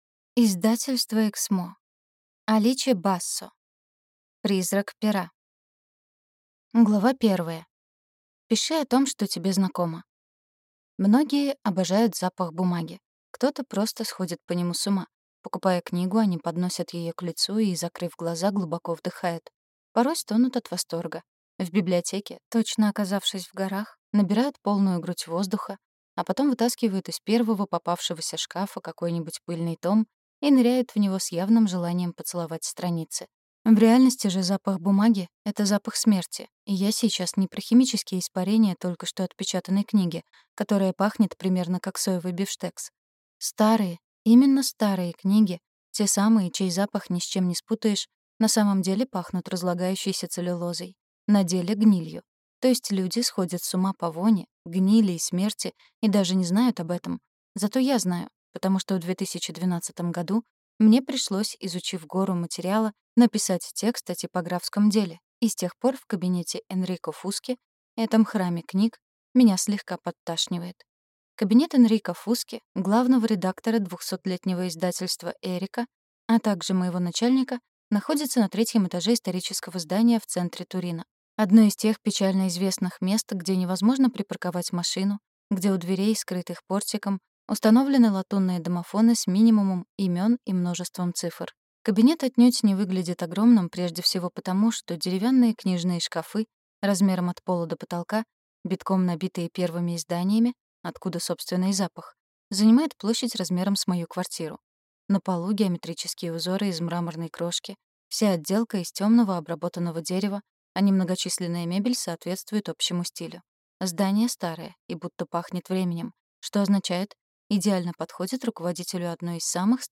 Аудиокнига Призрак пера | Библиотека аудиокниг
Прослушать и бесплатно скачать фрагмент аудиокниги